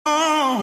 gravy-ad-lib.mp3